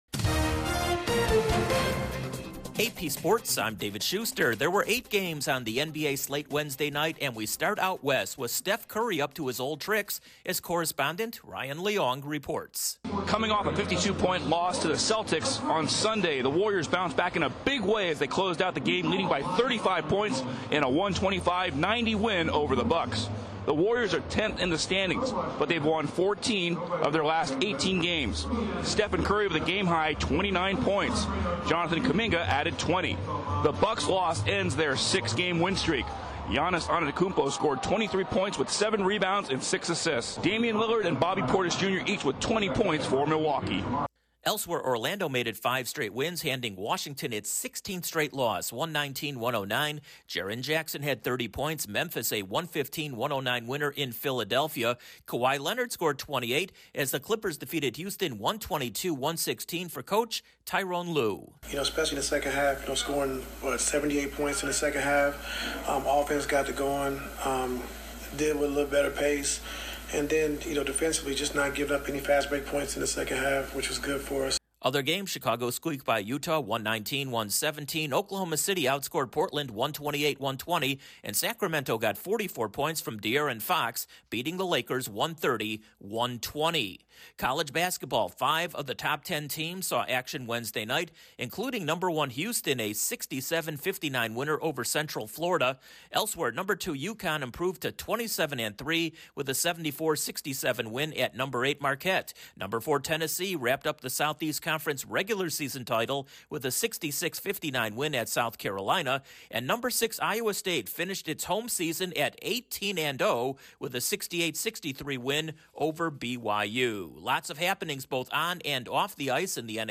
Eight games on the NBA slate Wednesday, five of the top ten teams in college basketball saw action, there was action both on and off the ice in the NHL and a flurrly of moves in the NFL. Correspondent